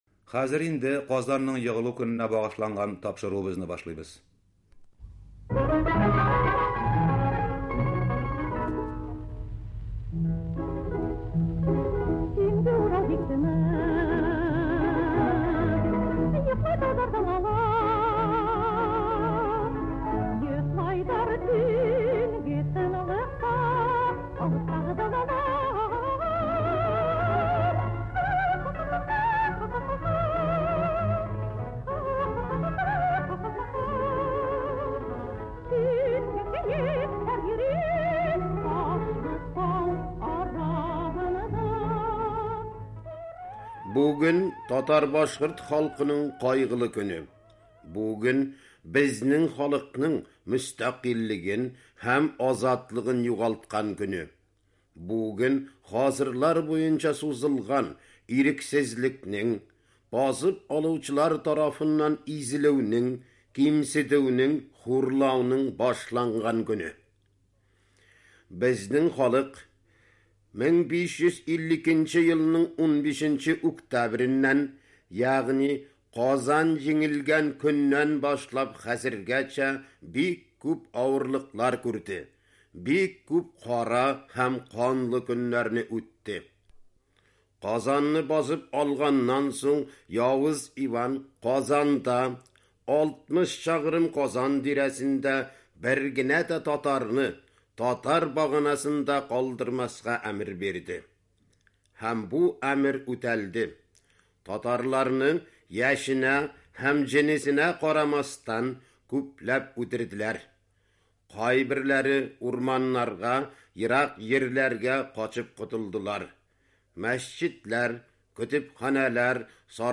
Кыска язмада диктор Казан ханлыгының яулап алынуы һәм аның нәтиҗәләре турында сөйли. Шул ук вакытта ул татар халкының хәзергәчә үзен милләт буларак саклап килүен, ата-бабаларның милләтне авыр чакта да саклап калуын искәртә.